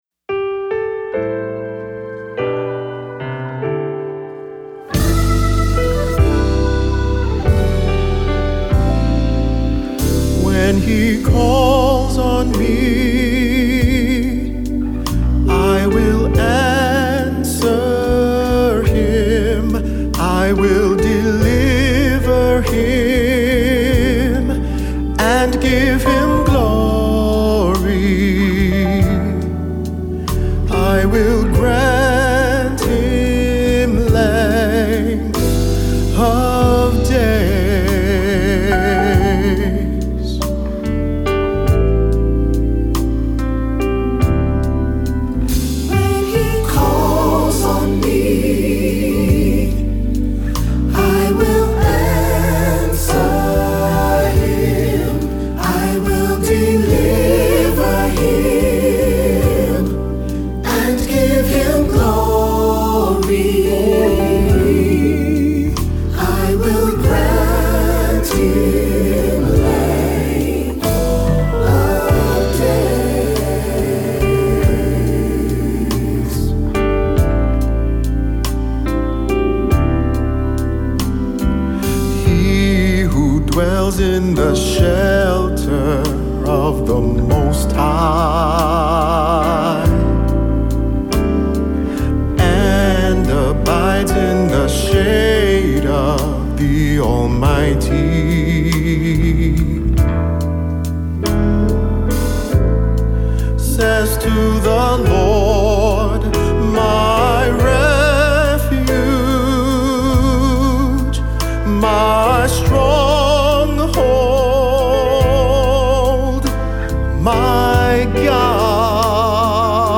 Voicing: SATB; Cantor; Solo; Assembly